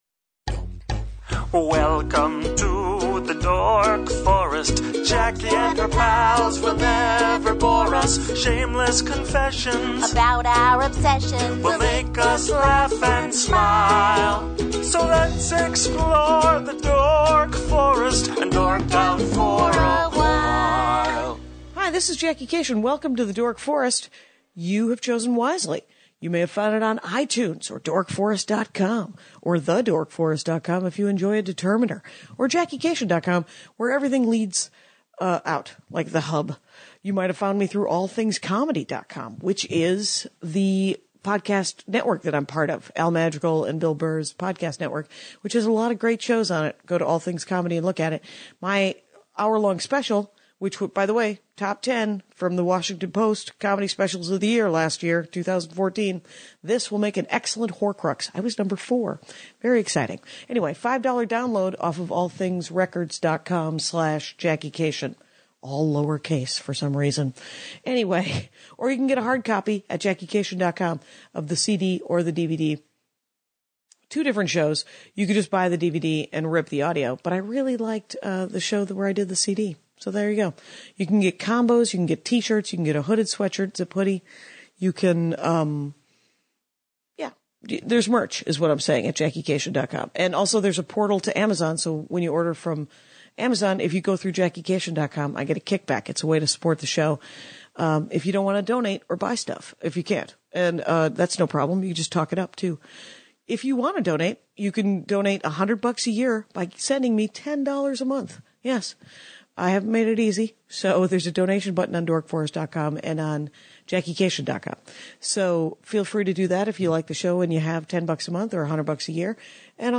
She reads HER OWN AD.